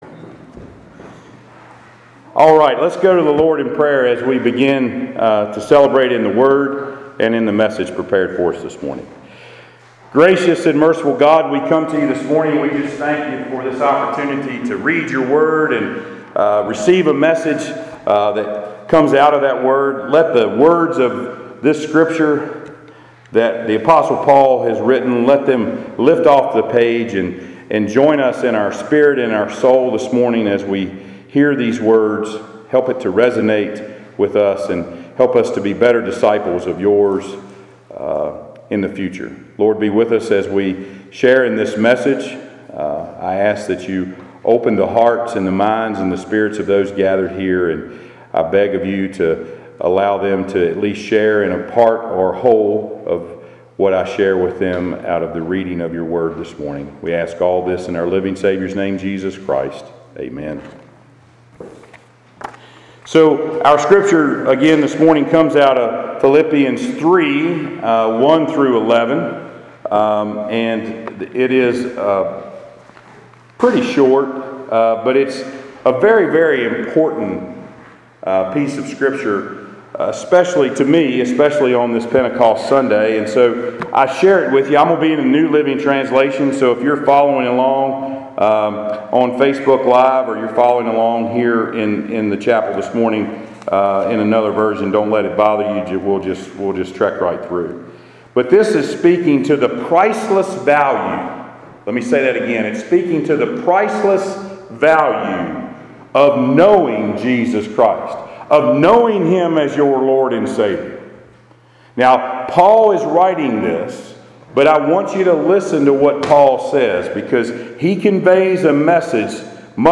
Passage: Philippians 3: 1 - 11 Service Type: Sunday Worship